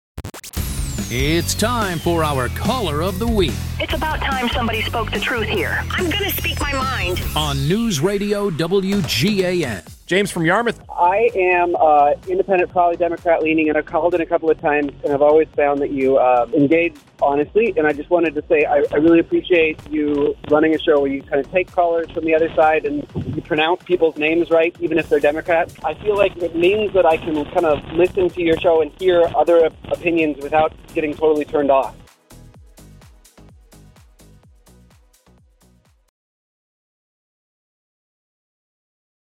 one person calling into the Morning News, who offers an interesting take, a great question or otherwise bring something to the discussion that is missing.